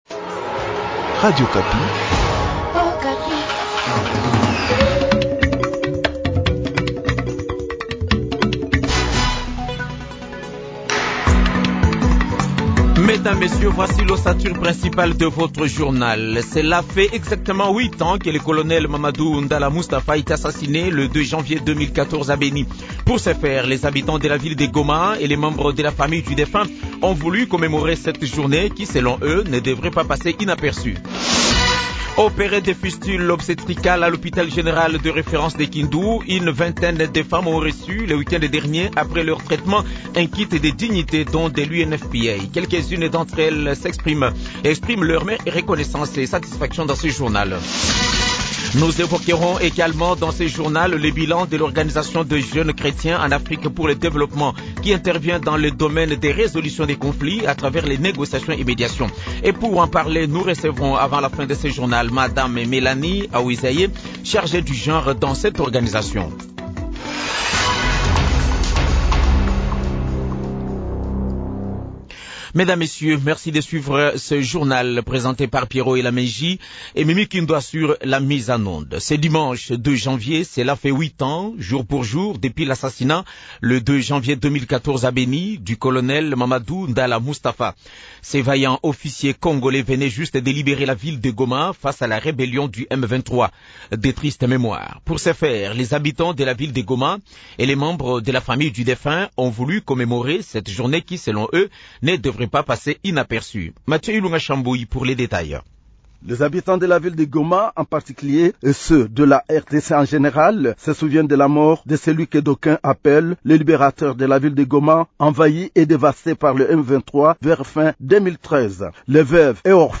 Journal midi